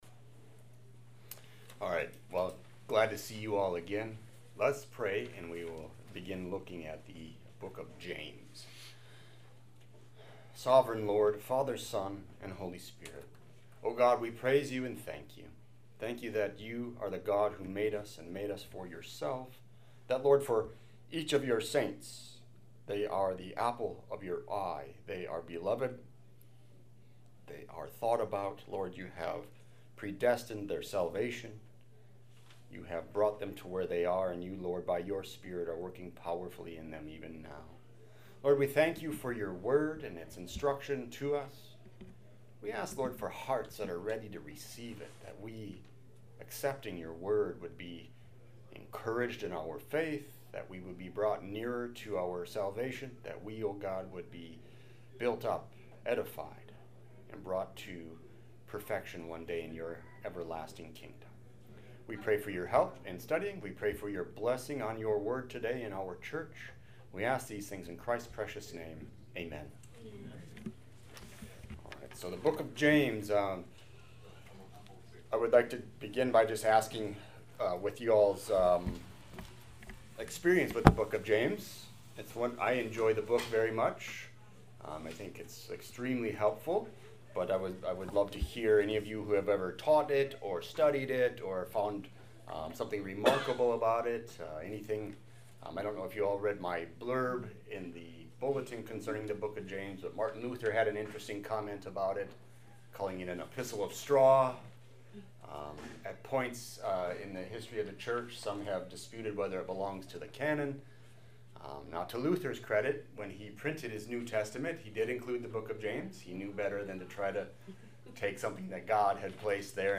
00:00 Download Copy link Sermon Text James 1